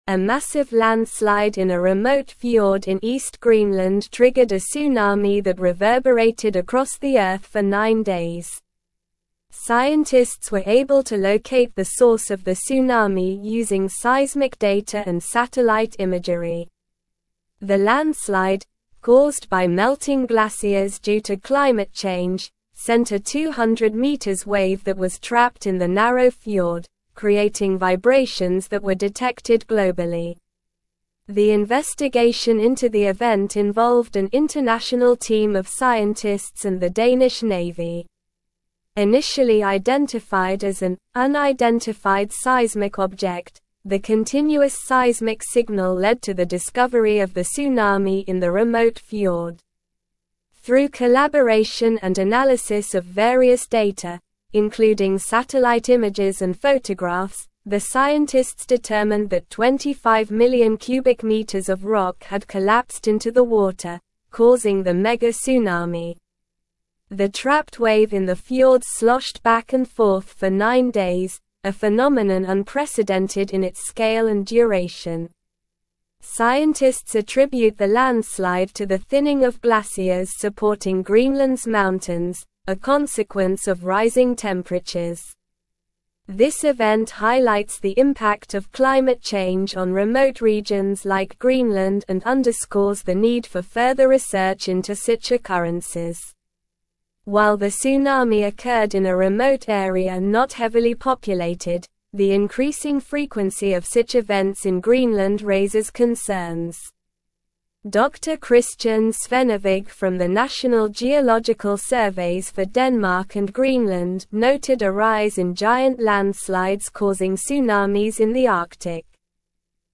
Slow
English-Newsroom-Advanced-SLOW-Reading-Greenland-Tsunami-Scientists-Unravel-Cause-of-Mysterious-Tremors.mp3